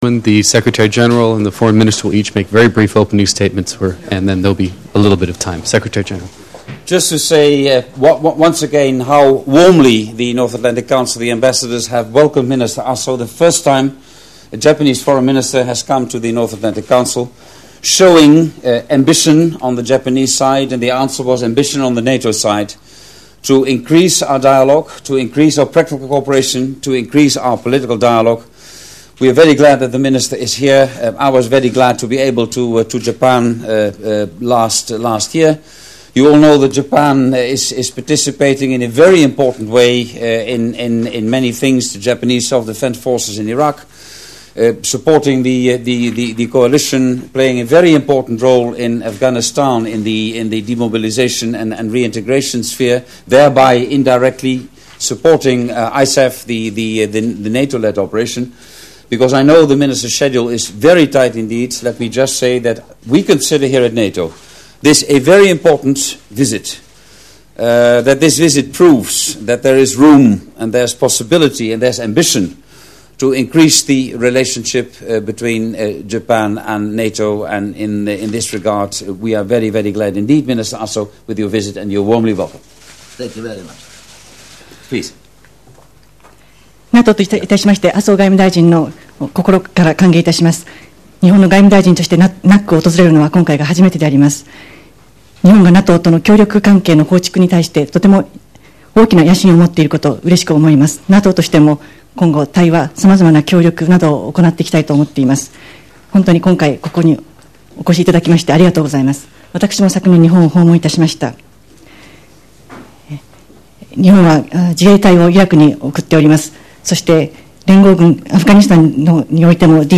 Audio Joint press point with NATO Secretary General Jaap de Hoop Scheffer and Taro Aso, Minister of Foreign Affairs of Japan, opens new window